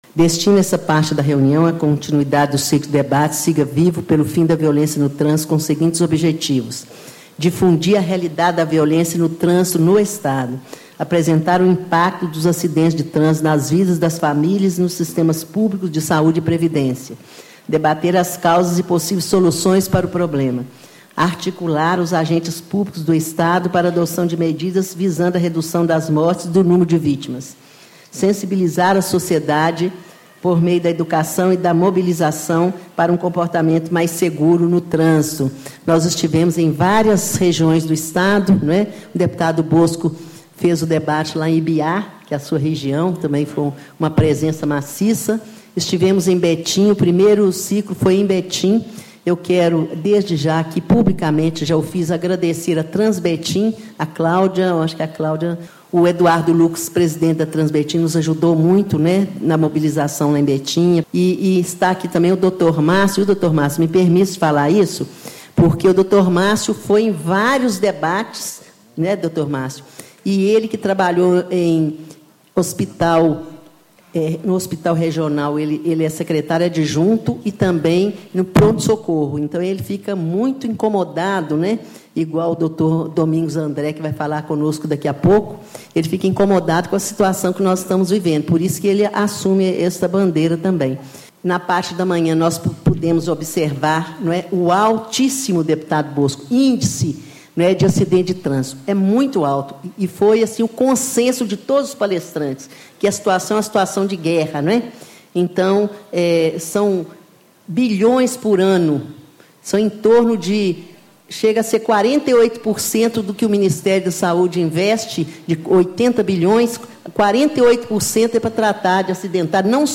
Deputada Maria Tereza Lara, PT, Vice-Presidente da Comissão de Segurança Pública
Discursos e Palestras